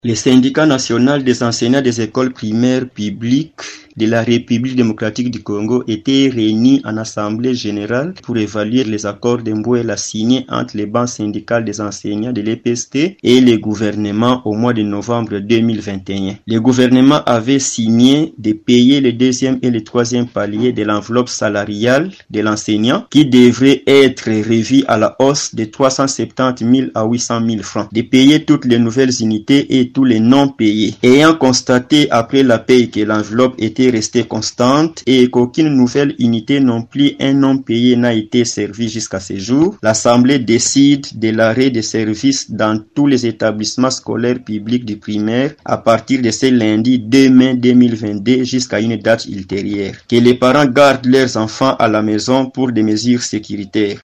a lu devant la presse la déclaration adoptée lors de cette assemblée générale